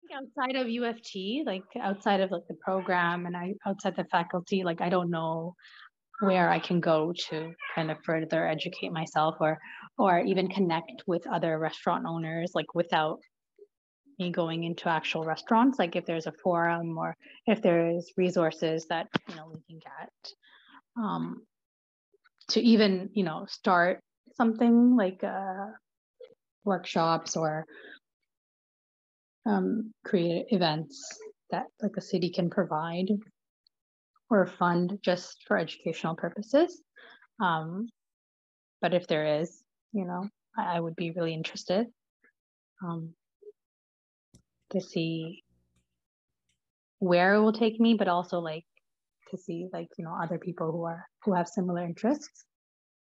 Un entretien
(interviewée)
(intervieweur)